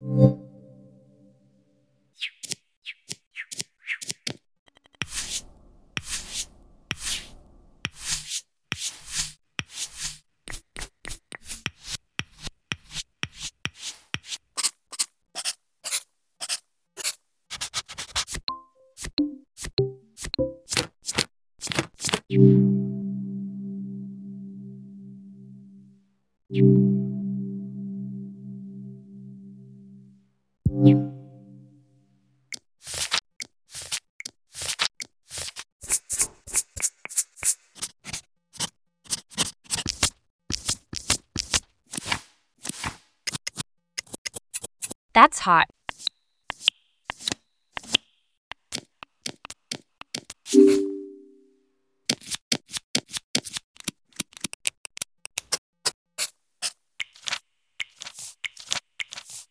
Attached: 1 audio that thing I'm always compelled to do... (lining all of an app's wee notification sound files up end to end) sicked on Craft.